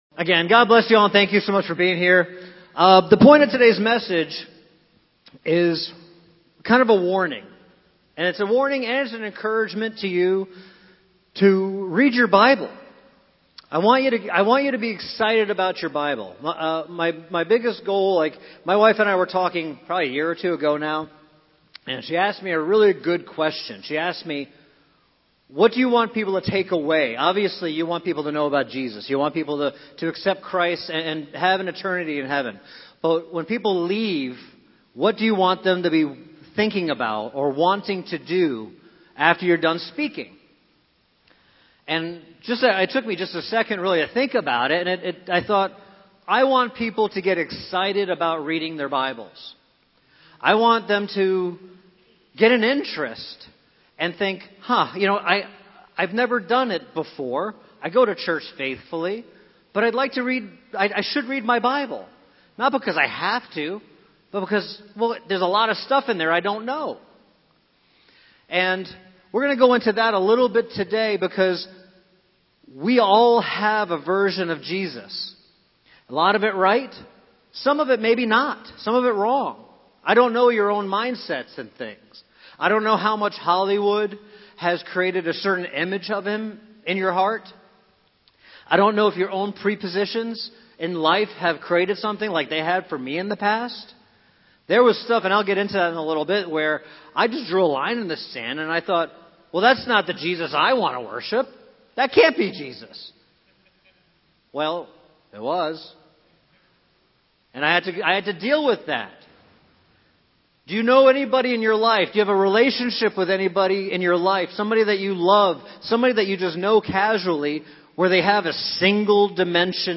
The latest sermons of Fellowship Church in Englewood, FL.